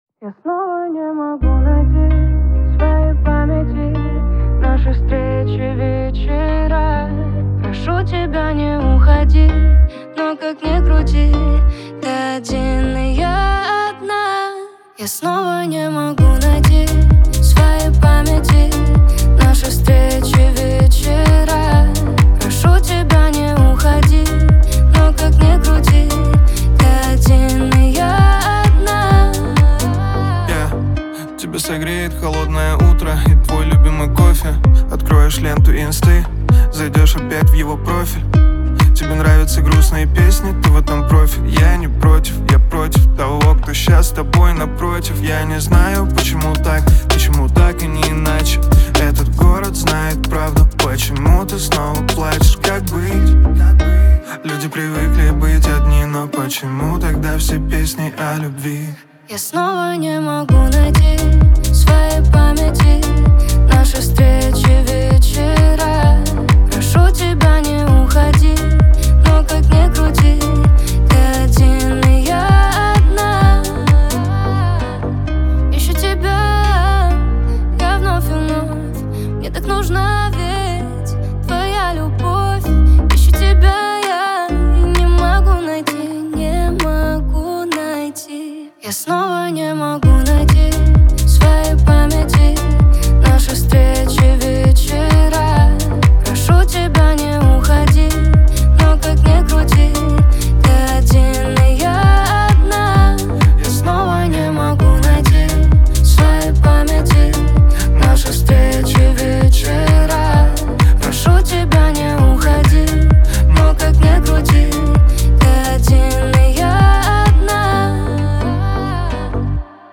дуэт , грусть
Лирика
pop